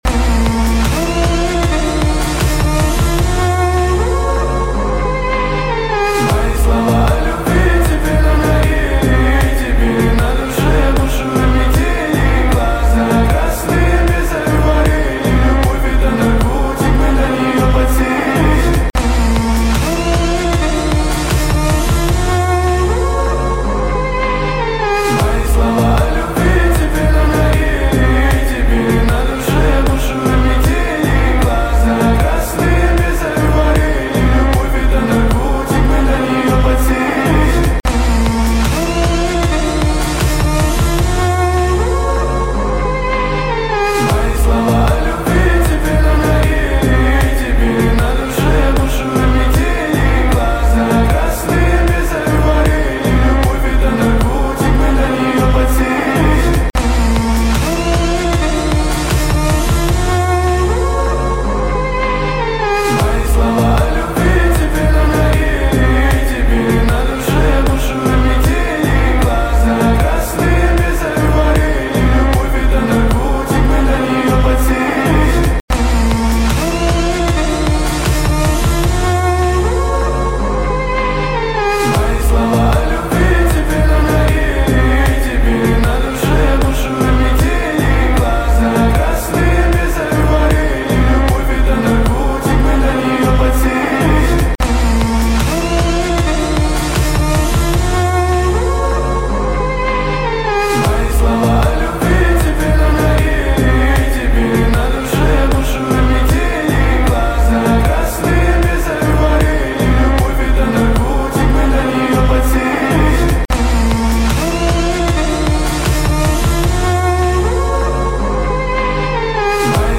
Качество: 320 kbps, stereo
Поп музыка